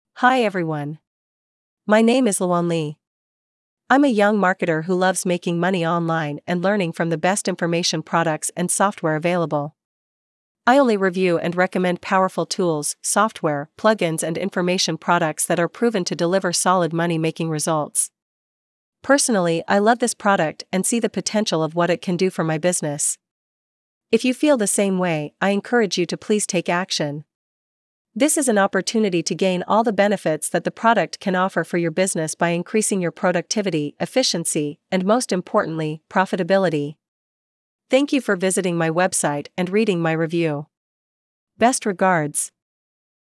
Here, let me show you the simple steps to use this brilliant tool and quickly turn any text into a realistic human-sounding voice in just 4 easy steps:
Let’s check my demo audio below:
Invest a few bucks, and you can own a powerful yet user-friendly AI tool to convert any text to natural sounding voices within a few mouse-clicks.
Micmonster-Audio-Demo.mp3